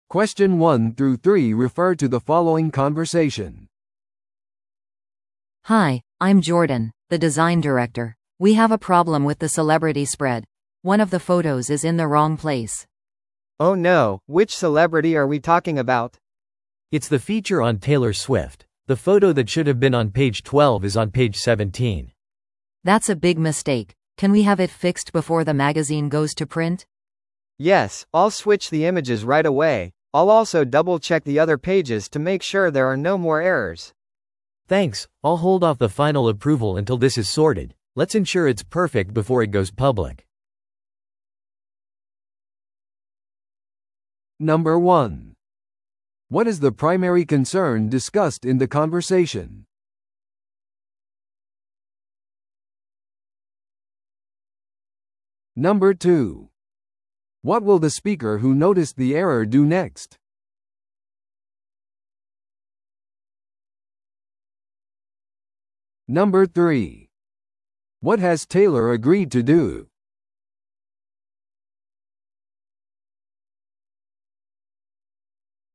No.1. What is the primary concern discussed in the conversation?